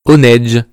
Cries
HONEDGE.mp3